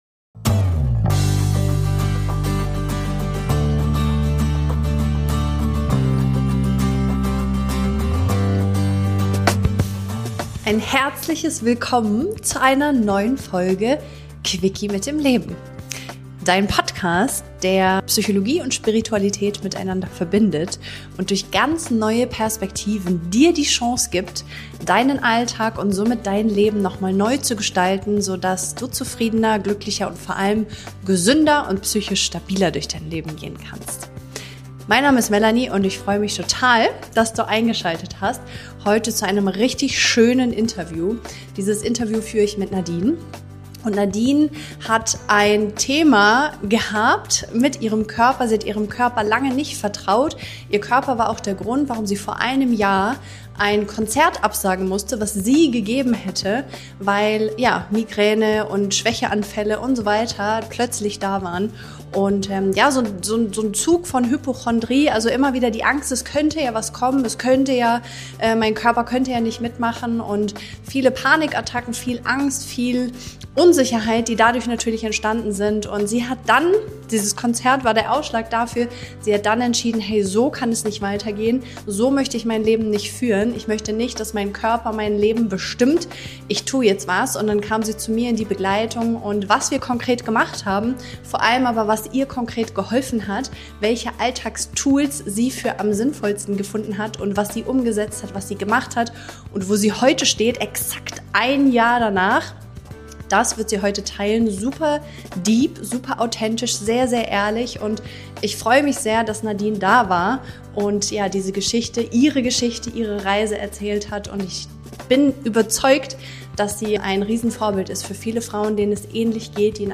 Du erfährst, wie körperliche Symptome mit innerem Druck, Nervensystem und Kontrolle zusammenhängen – und warum Heilung nicht bedeutet, schneller zu funktionieren, sondern ehrlicher zu fühlen. Ein tiefes Gespräch über Angst, Selbstverbindung und die Entscheidung, sich nicht länger vom eigenen Körper bestimmen zu lassen.